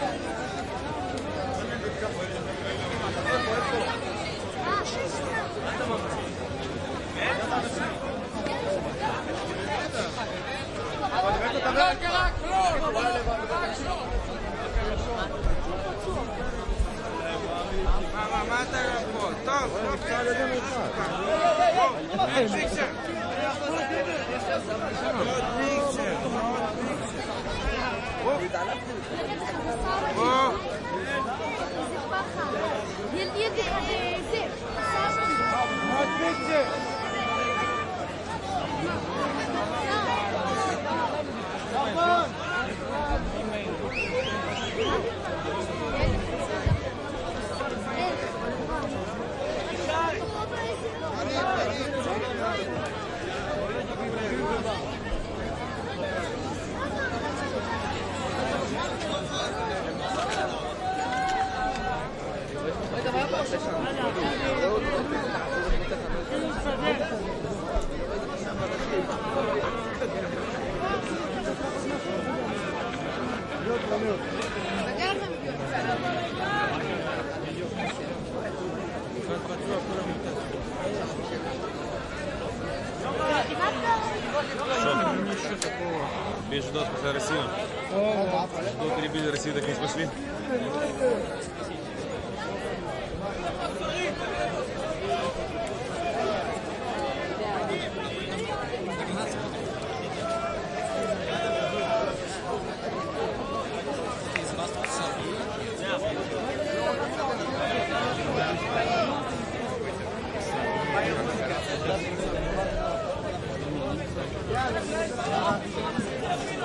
酒吧餐厅打烊，喋喋不休，眼镜，瓦拉，中等成人杂音
描述：纽约市夜总会在凌晨4点关闭，人群沃拉，保镖，厨房工作人员
Tag: 眼镜 喋喋不休 关闭 杂音 成人 聊天 餐厅 人群 酒吧 媒体 沃拉